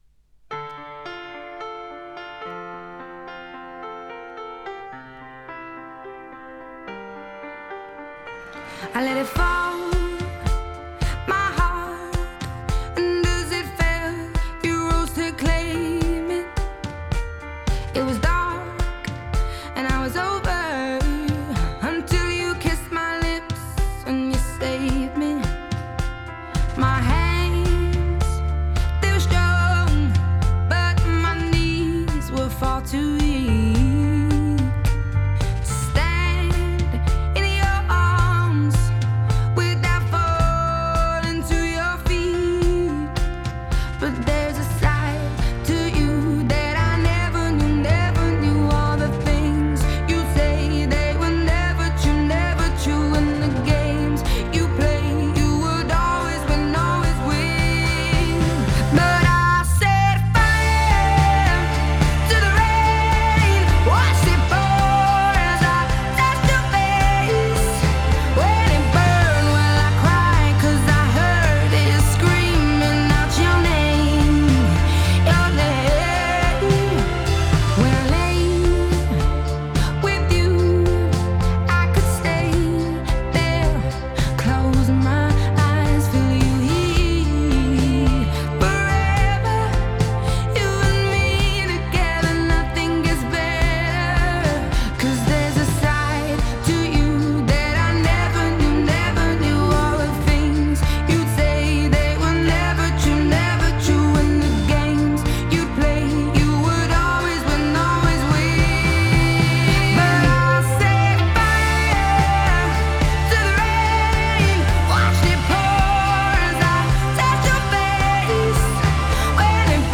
Genre: Pop, Blues